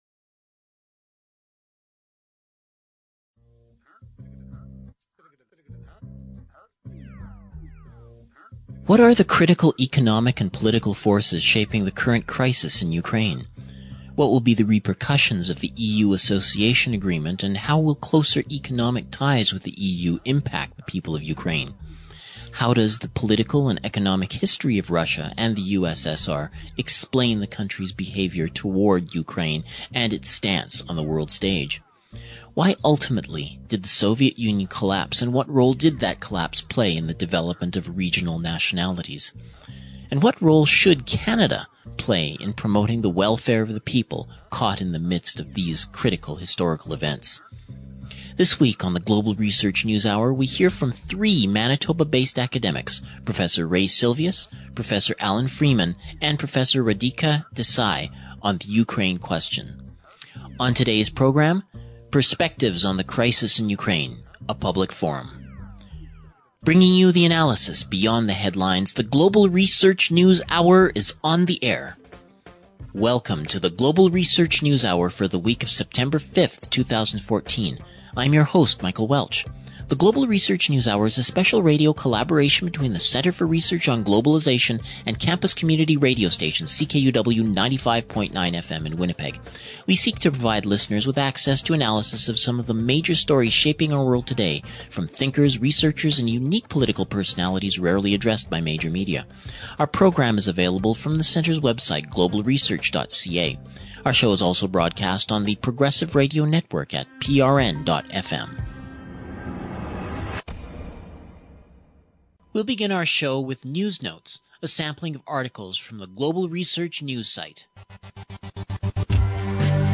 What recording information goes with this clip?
Perspectives on the Crisis in Ukraine - A Public Forum